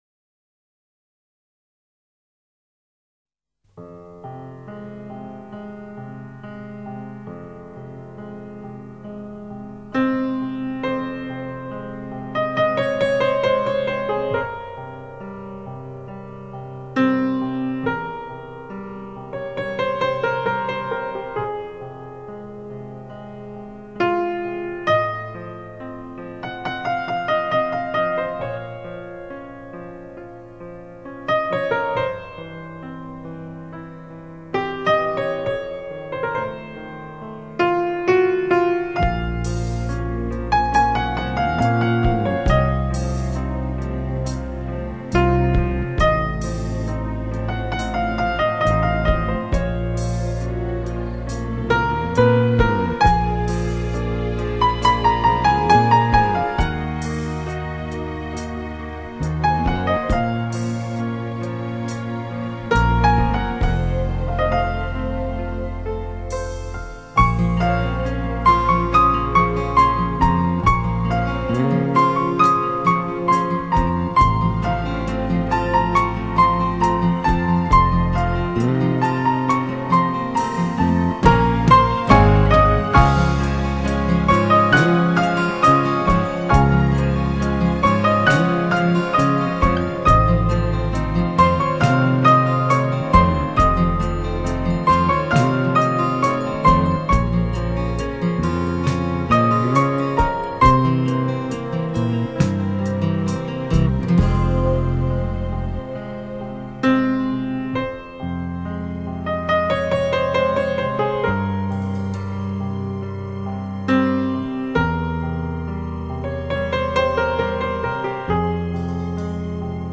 美。里面收录的曲目大都是在过去几十年间在欧美流行的歌曲改编的器乐曲，